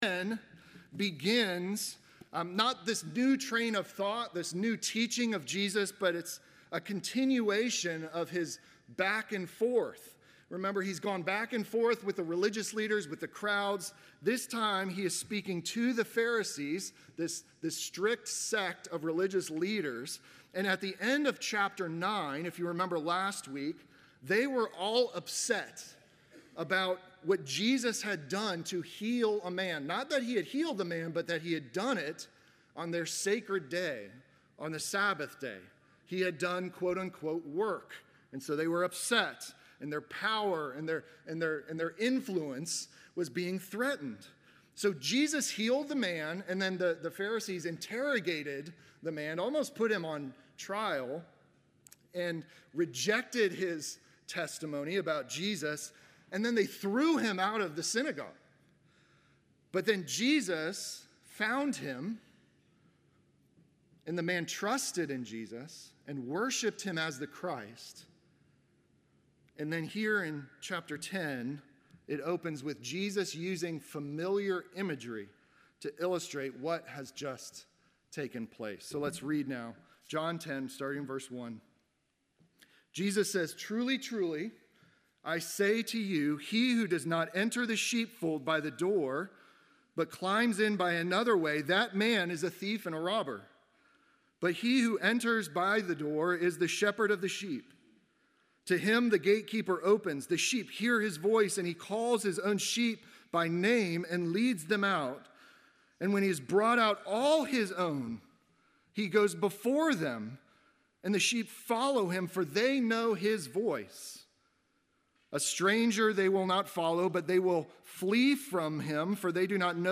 Sermon from October 12